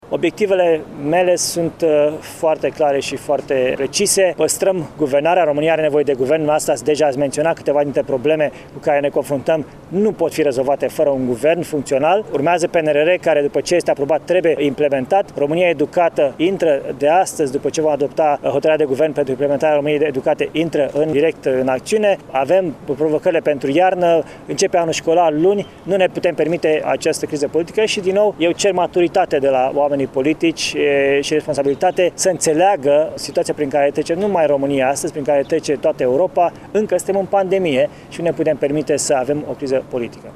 În conferinţa de presă care a urmat întâlnirii cu liberalii ieşeni, Florin Cîţu s-a referit, între altele, la creşterile de preţuri constatate în ultima perioadă.